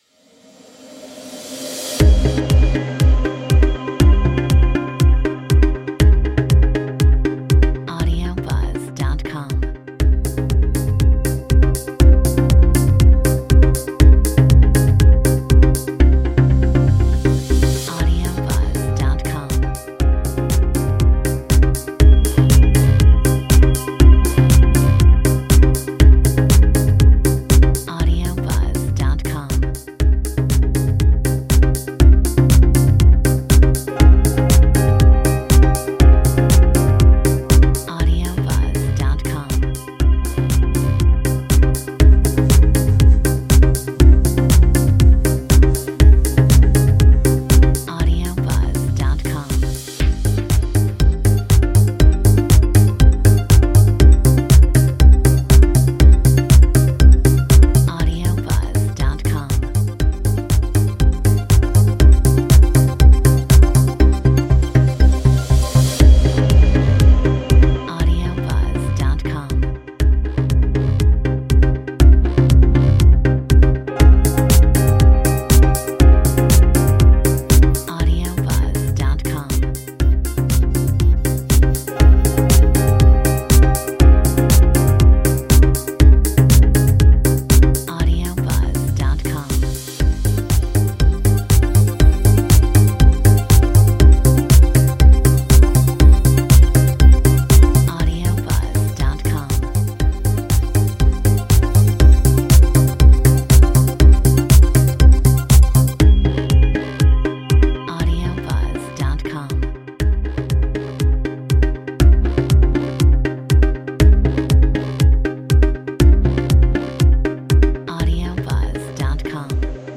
Metronome 120